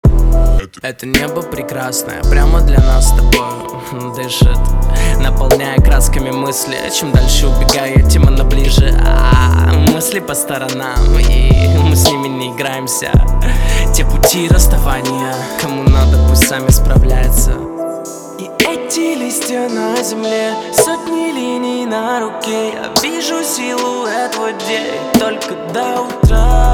• Качество: 320, Stereo
лирика
Хип-хоп
спокойные